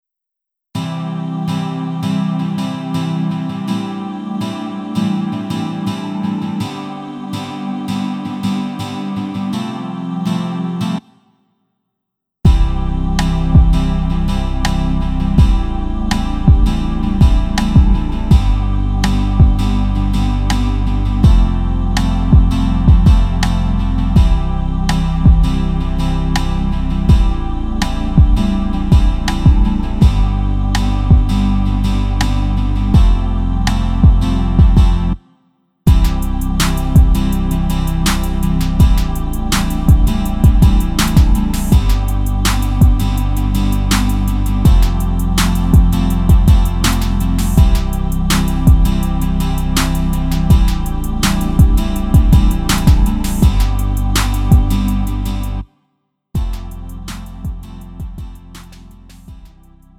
음정 -1키 2:59
장르 구분 Lite MR